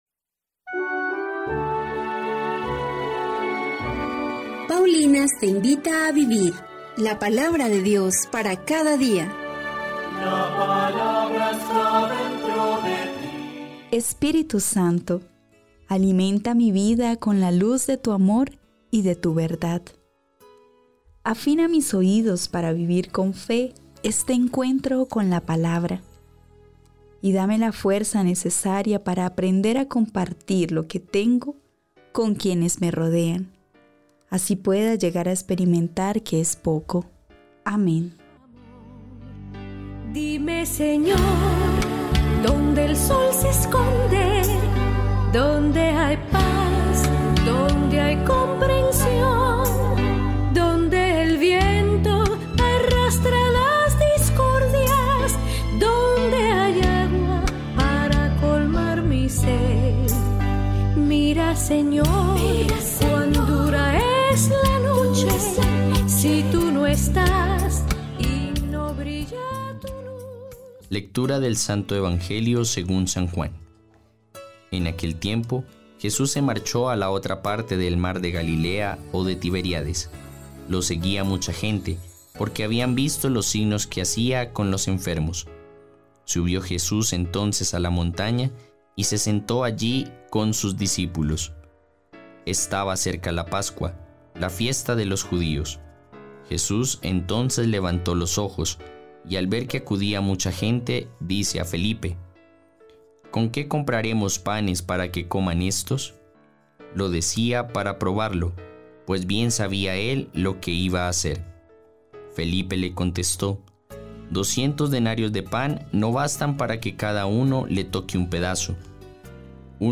Lectura de la Primera Carta del apóstol san Juan 4, 7-16